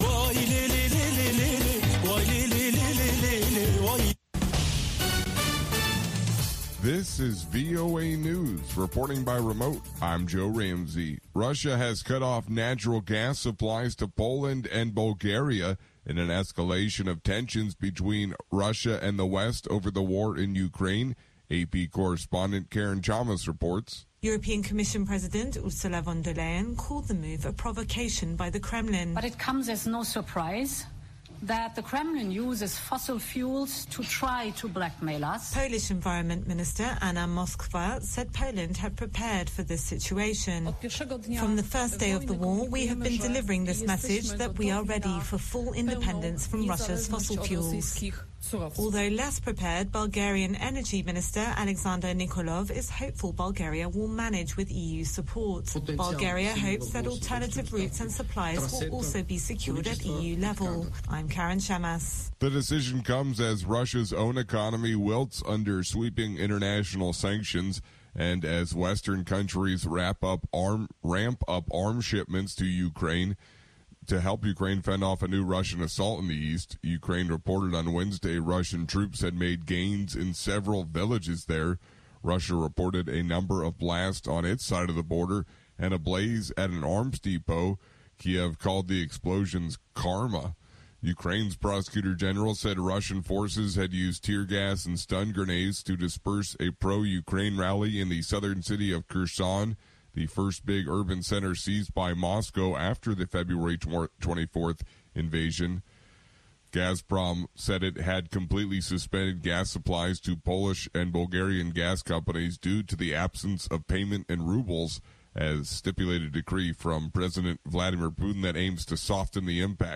Nûçeyên 3’yê paşnîvro